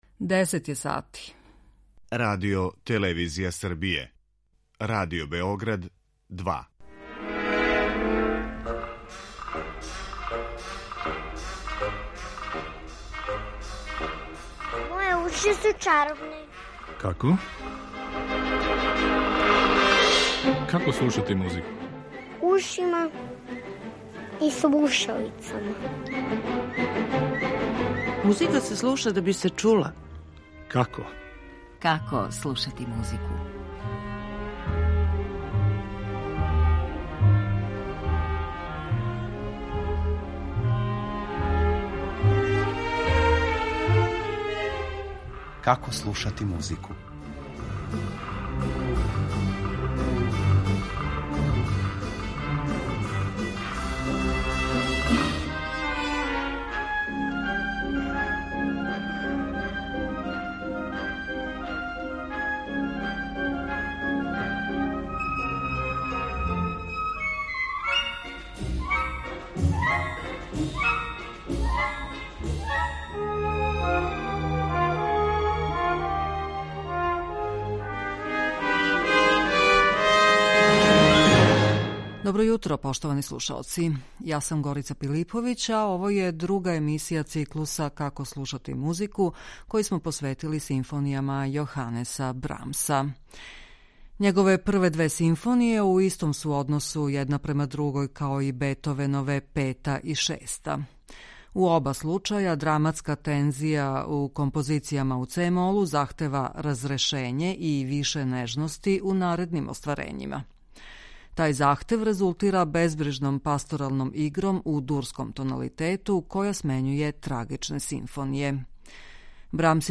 Тај захтев резултира безбрижном пасторалном игром у дурском тоналитету која смењује трагичне симфоније. Брамс је ипак за своје друго остварење тог жанра рекао да поседује и извесну дозу меланхолије.